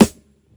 • Small Reverb Steel Snare Drum Sample A Key 62.wav
Royality free snare drum sample tuned to the A note.
small-reverb-steel-snare-drum-sample-a-key-62-Q1u.wav